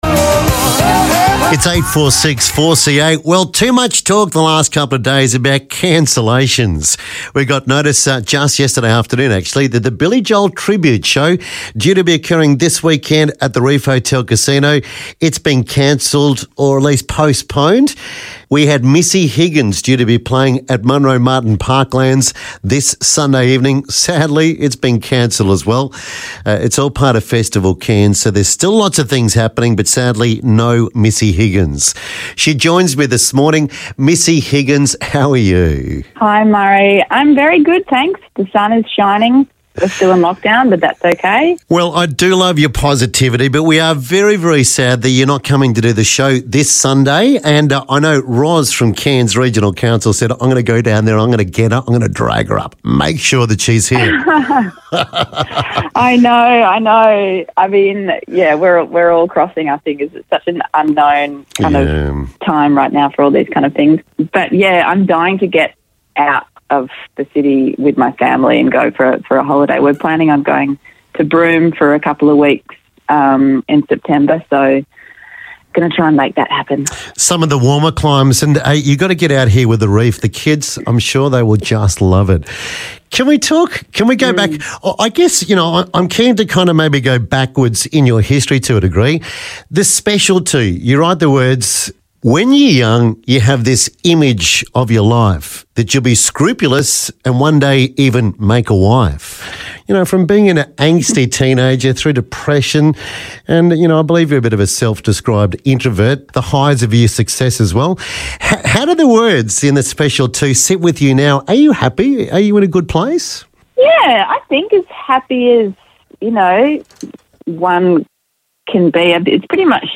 Chatting with Missy Higgins